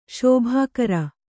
♪ śobhākara